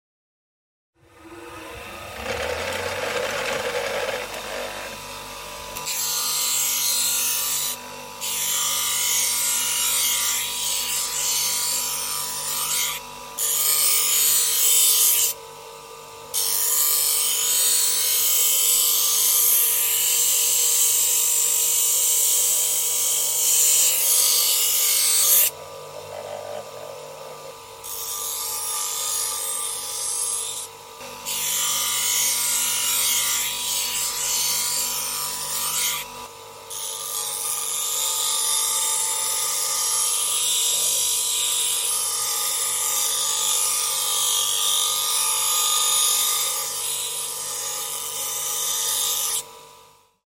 ⚙ SONIDO DE RADIAL – sound effects free download
Escucha el sonido real de una radial o amoladora cortando metal.
🎧 Audio realista de corte con chispas.